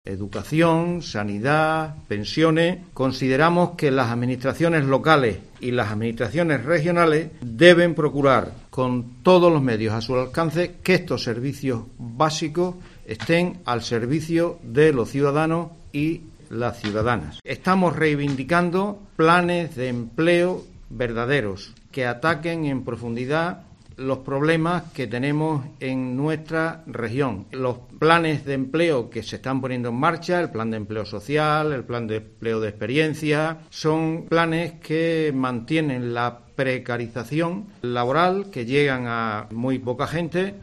en rueda de prensa